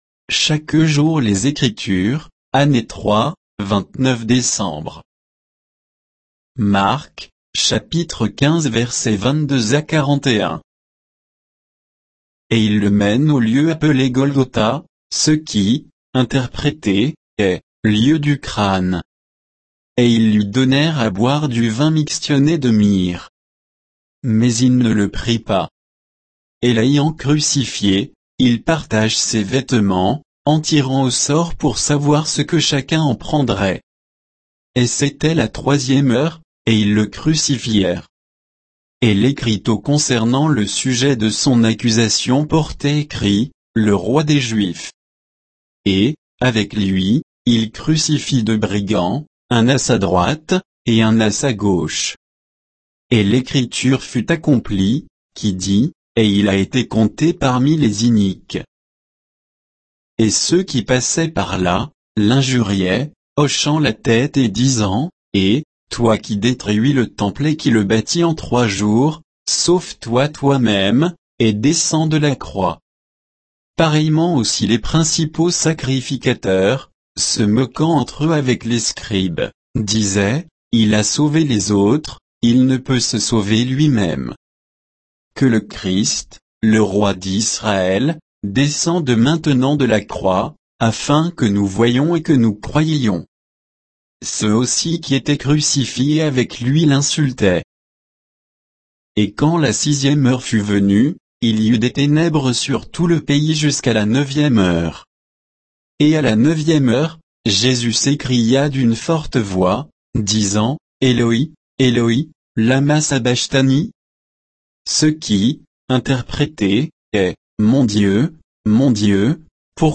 Méditation quoditienne de Chaque jour les Écritures sur Marc 15, 22 à 41